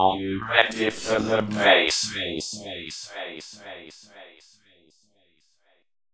robotvocals " areuready4dbass
描述：创作时使用了espeak的基本人声，然后用rubberband进行时间拉伸，最后在audacity中用回声、相位器、高通滤波器、混响等特效进行编辑。
Tag: 外星人 大胆 低音 回声 准备好 拉伸 时间 未来 espeak的 机器人 声音